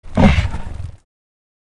Вы можете слушать и скачивать рычание, крики, шум крыльев и другие эффекты в высоком качестве.